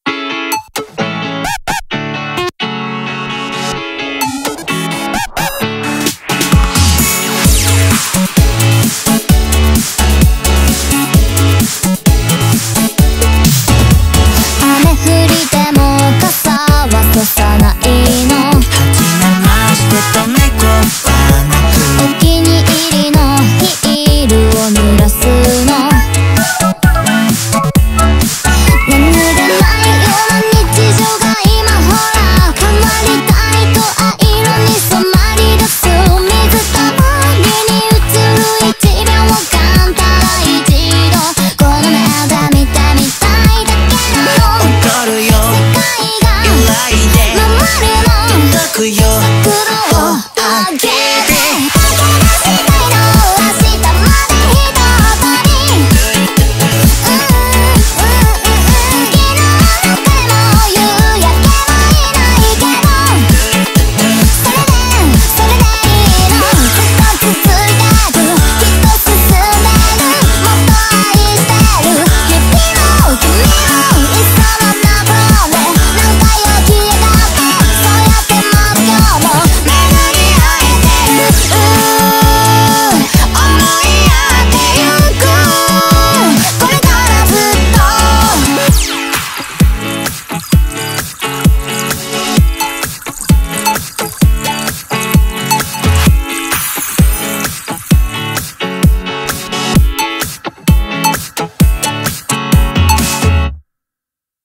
BPM130
Audio QualityPerfect (High Quality)
Comments[J-POP]